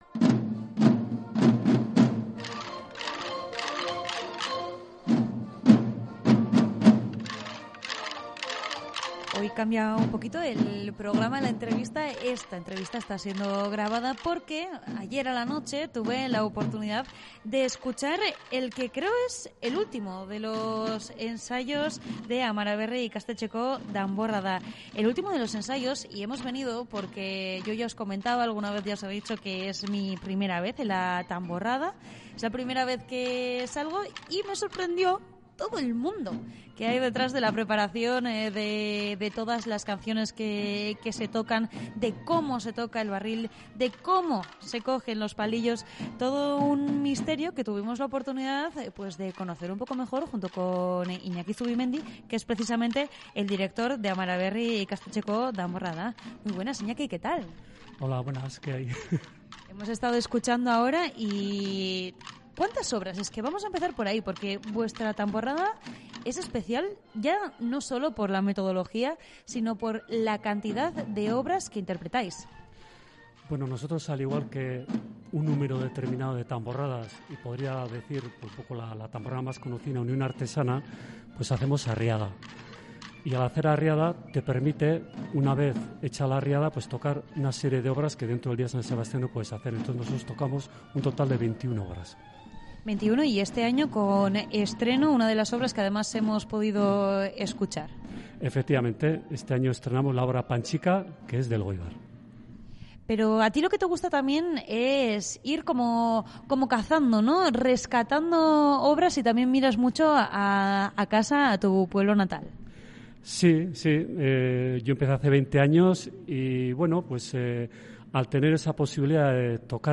En Onda Vasca, nos hemos colado en el último de los ensayos de la tamborrada de 'Amara Berri Ikastetxeko Danborrada'.